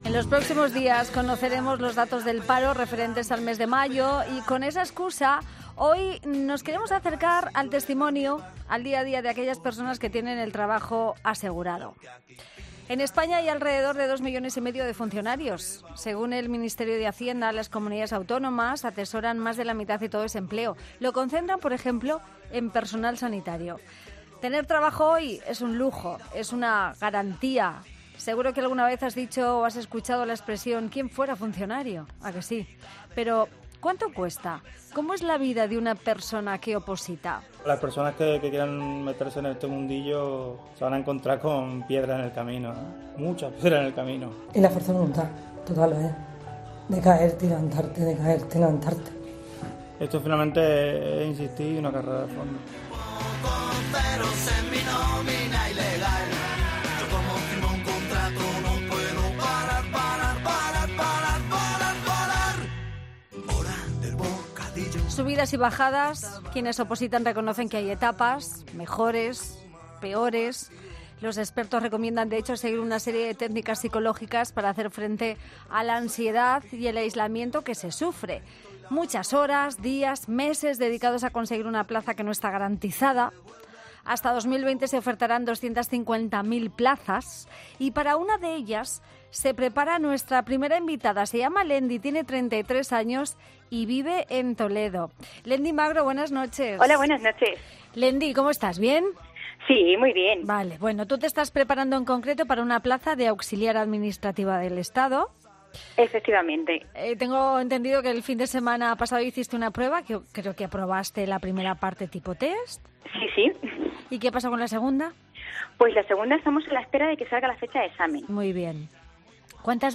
No te pierdas la entrevista y conoce todos los detalles acerca de este mundillo al que mucha gente se acerca para conseguir un puesto de trabajo.